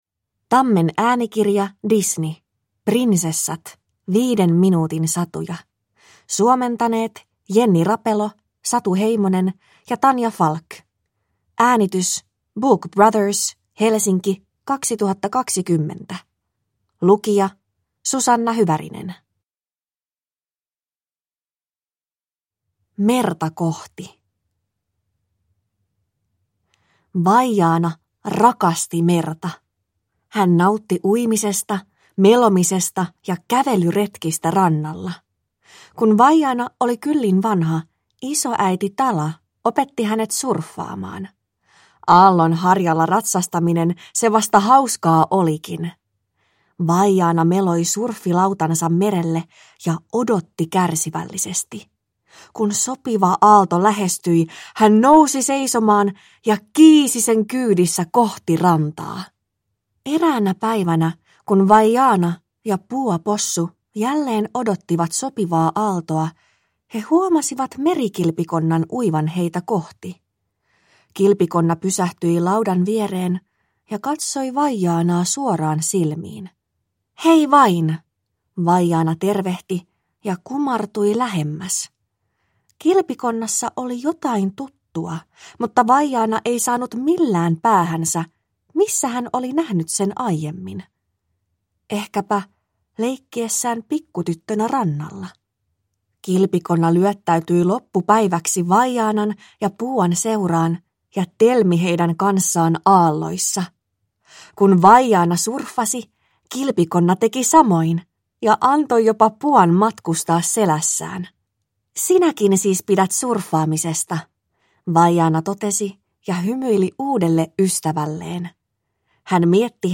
Disney Prinsessat. 5 minuutin satuja – Ljudbok – Laddas ner